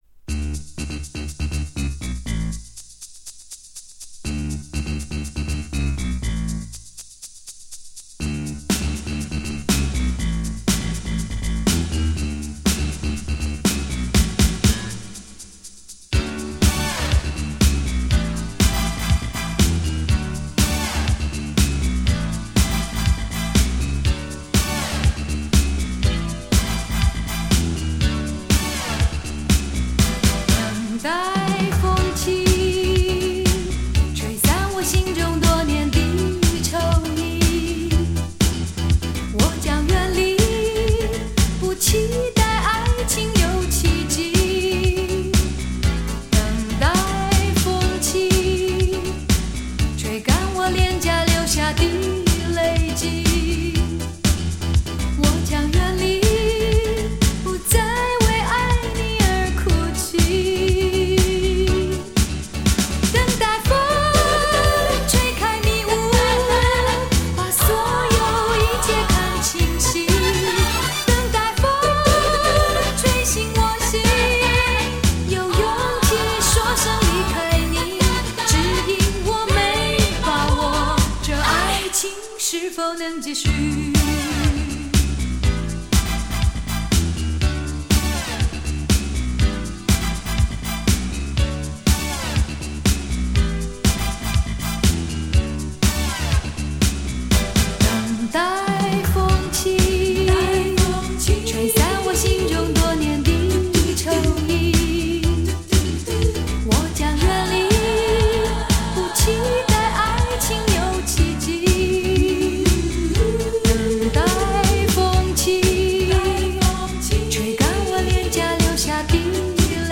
其中收录多首温柔婉约的小品式作品。